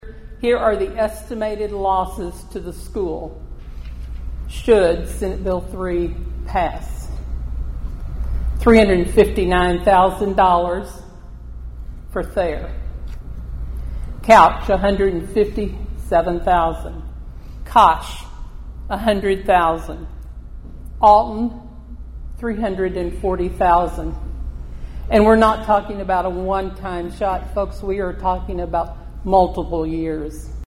A Countywide Town Hall Meeting was held last night at the Thayer High School.